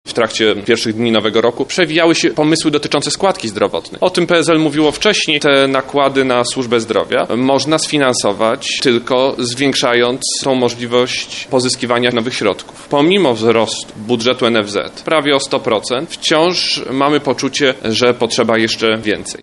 Zmiany na pewno nie będą dotyczyć składki na ubezpieczenie społeczne, przyznał Władysław Kosiniak-Kamysz, Minister Pracy i Polityki Społecznej podczas wczorajszego Wojewódzkiego Opłatka Ludowego.
Warunkiem do zmian dotyczących składki zdrowotnej jest nie tylko wola polityczna, ale też porozumienie społeczne – mówi Władysław Kosiniak-Kamysz.